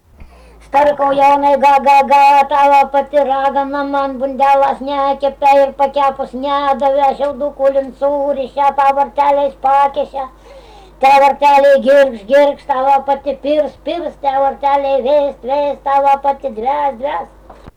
smulkieji žanrai
Ryžiškė
vokalinis